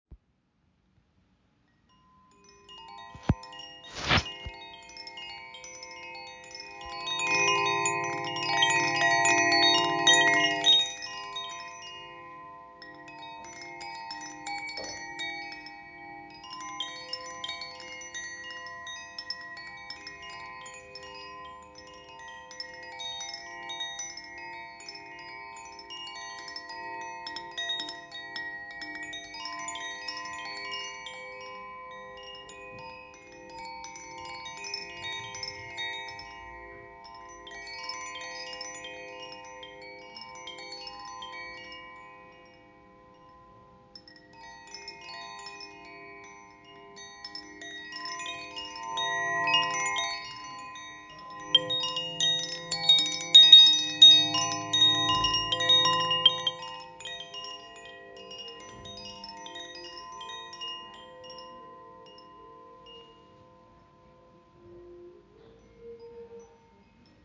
Handgefertigtes Windspiel aus Aluminium, Stahl und Buchenholz
Tonfolge: F Minor in 432Hz
Acht Stäbe werden mit Silberschweissung gefasst, der Aluminiumkörper geformt wie eine Resonanzglocke – und zusammen erschaffen sie ein Instrument voller Klarheit, Tiefe und schwebender Obertöne.